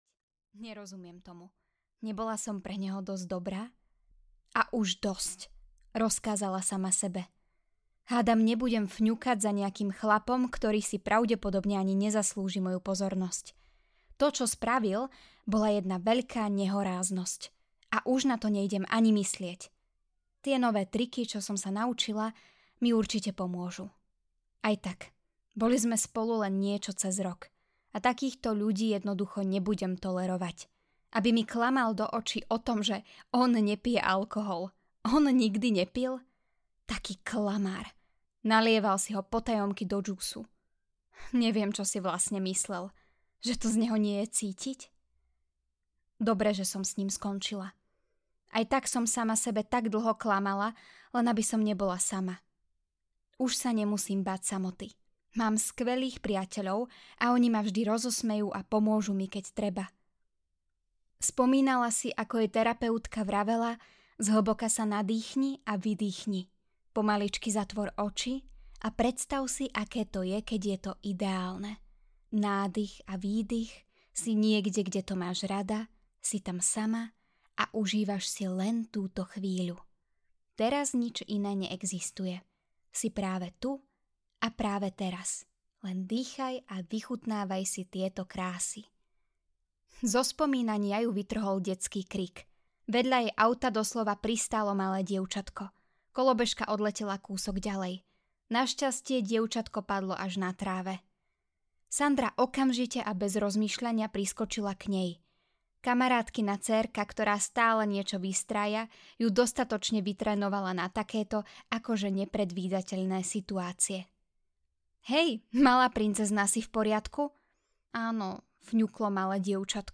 Bolesť a vášeň audiokniha
Ukázka z knihy